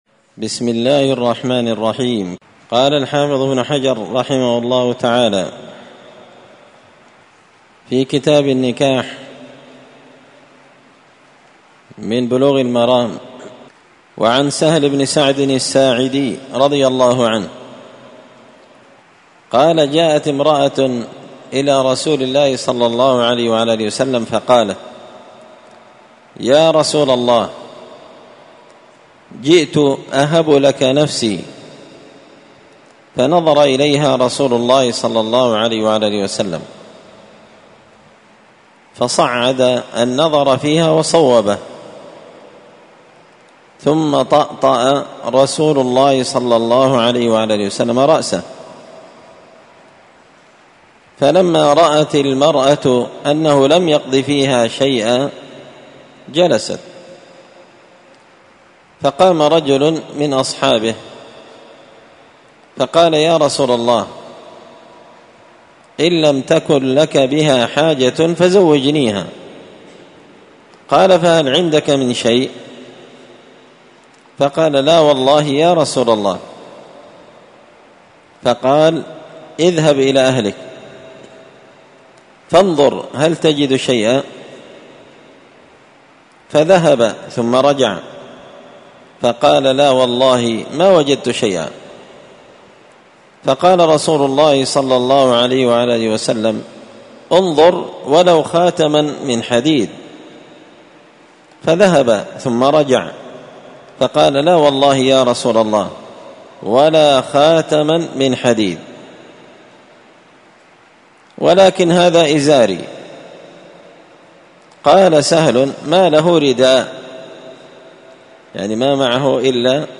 كتاب النكاح من سبل السلام شرح بلوغ المرام لابن الأمير الصنعاني رحمه الله تعالى الدرس – 5 تابع أحكام النكاح
مسجد الفرقان_قشن_المهرة_اليمن